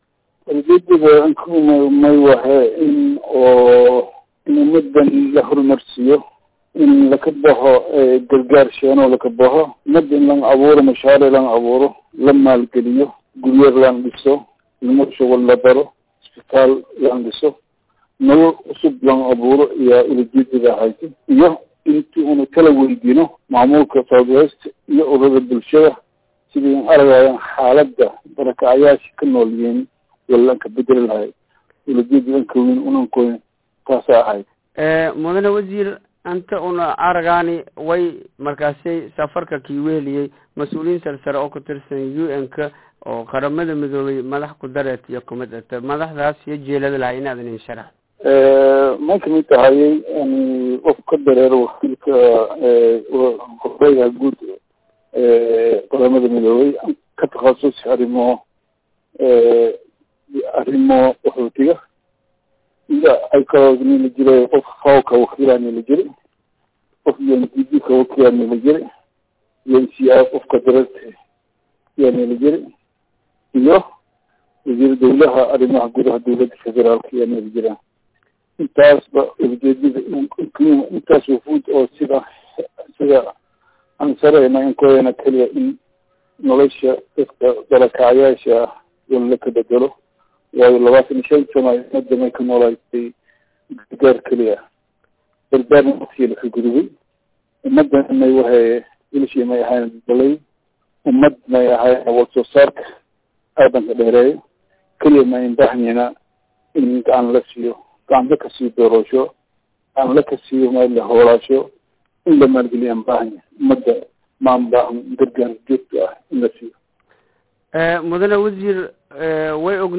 Ka dib markii uu soo dhamaadey Indha indhey Kaamamka Dadka Barakacayaasha ayaa waxaan wareysi gaar ah  aynu la yeelanay Wasiirka Howlaha Guud ee Soomaaliya Eng. Salax Sheekh Cismaan Muuse.